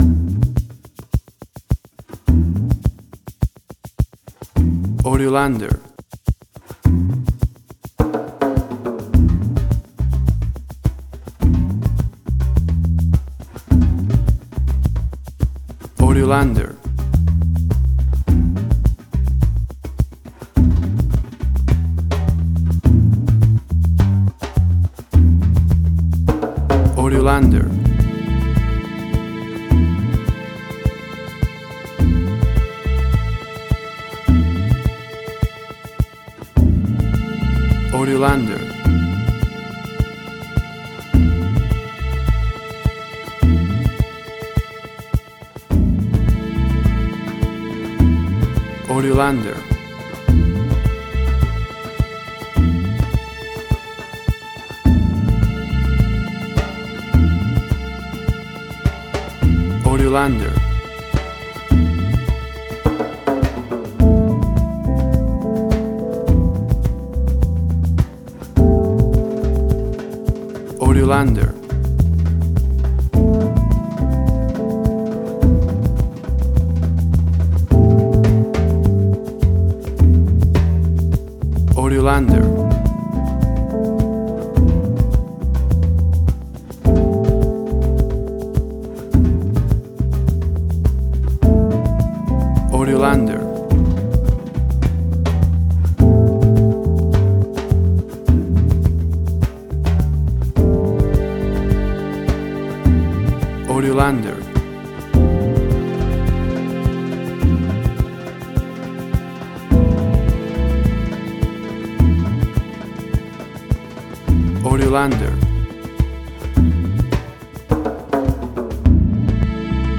Suspense, Drama, Quirky, Emotional.
Tempo (BPM): 105